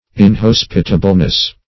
In*hos"pi*ta*ble*ness, n. -- In*hos"pi*ta*bly, adv.